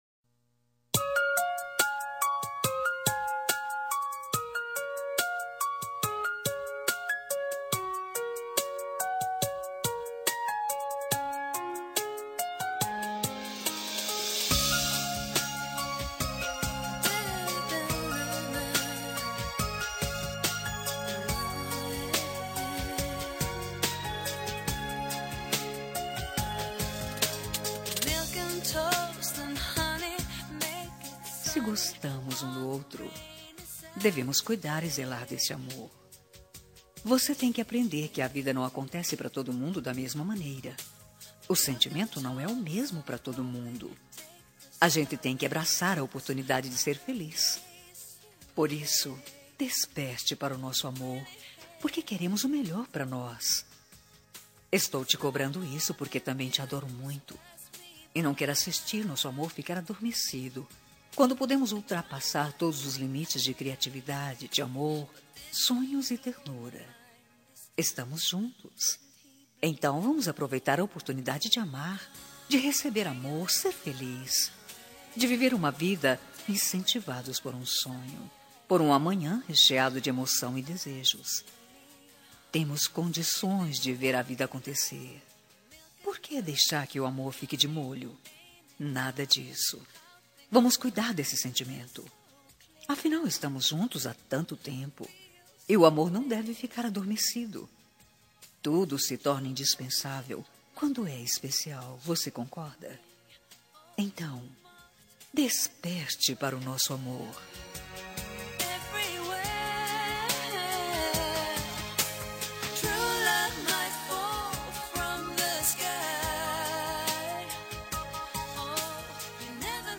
Telemensagem Relacionamento Crise – Voz Feminina – Cód: 5437